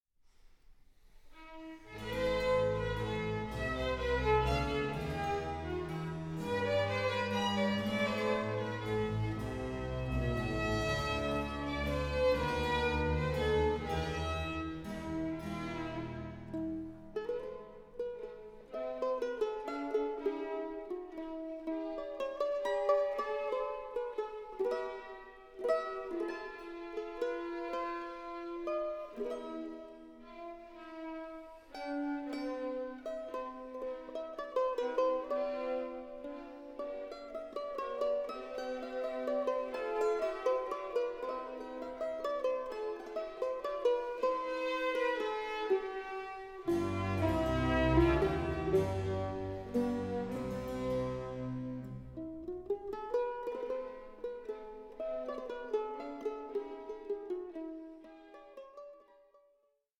works for mandolin and orchestra